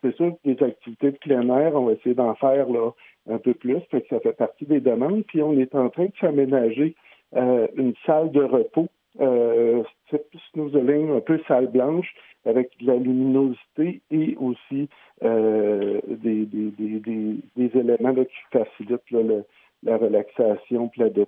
En entrevue avec le service e nouvelles de M105